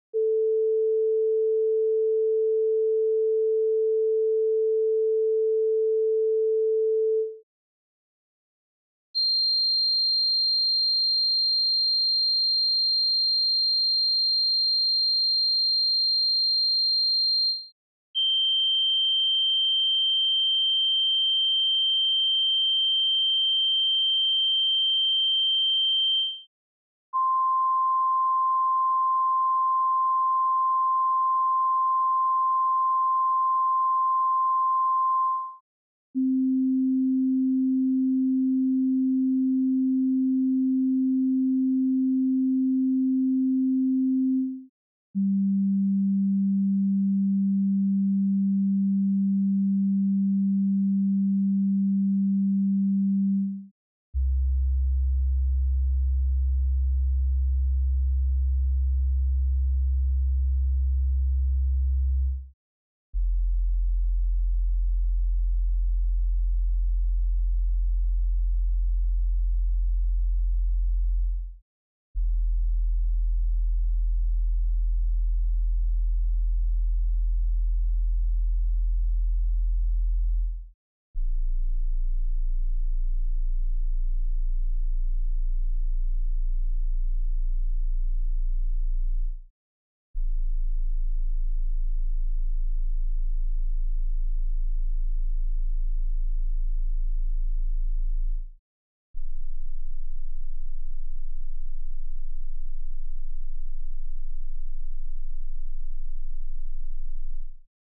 Suoni puri. Gamma frequenze musicali
1. Diapason (LA 440Hz)
2. Nota più alta piano (DO 4186Hz)
12. Nota più bassa organo (DO 16.3Hz)
Test Compact Disc - Music Frequencies.wav